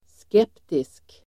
Ladda ner uttalet
Uttal: [sk'ep:tisk]
skeptisk.mp3